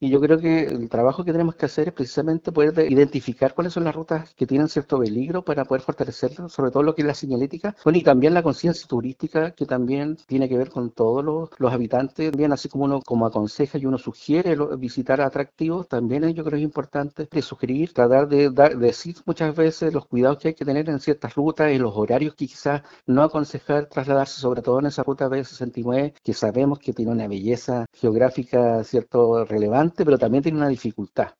Desde Sernatur también abordaron la situación, enfatizando la importancia del autocuidado por parte de los conductores que transitan por la zona, según señaló el director regional (s), Luis Hurtado.